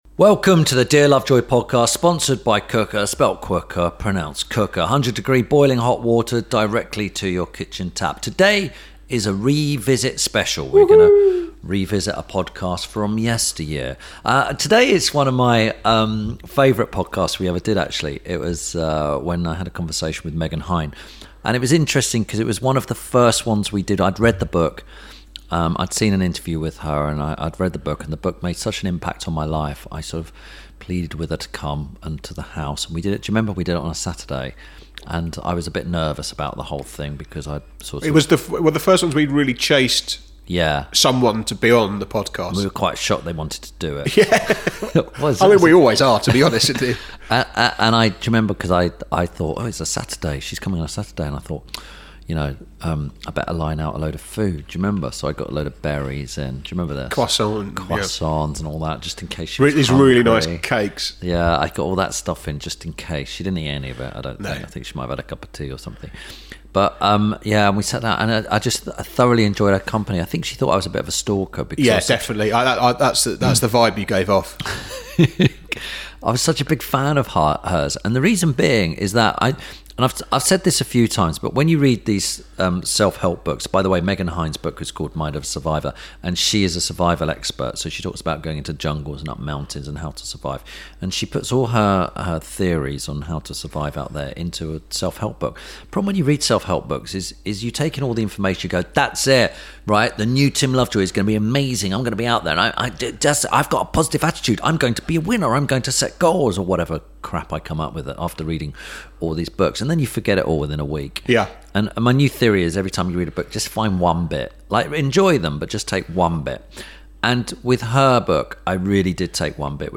Tim Lovejoy chats to survival expert and expedition leader Megan Hine. Tim and Megan discuss acceptance of your situation, taking responsibility and being face to face with lions, scorpions and drug cartels.